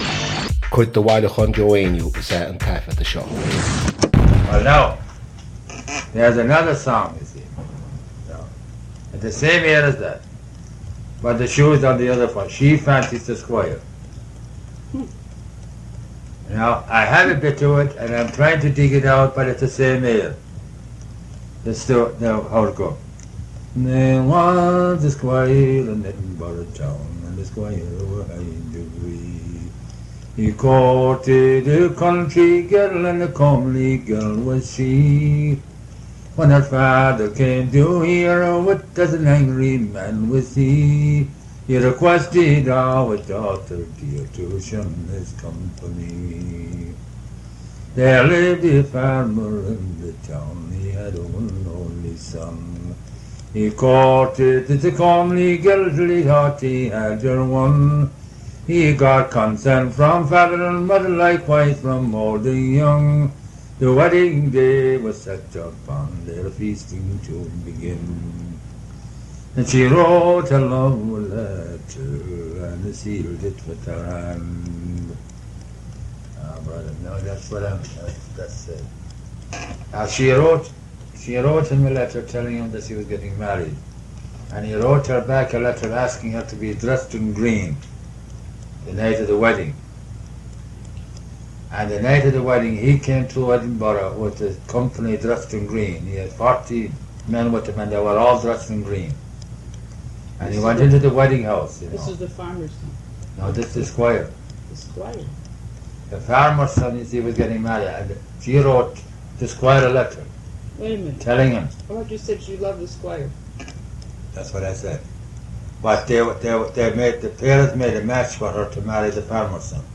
• Cnuasach (Collection): Joe Heaney Collection, University of Washington, Seattle.
• Catagóir (Category): song.
• Ainm an té a thug (Name of Informant): Joe Heaney.
• Suíomh an taifeadta (Recording Location): Bay Ridge, Brooklyn, New York, United States of America.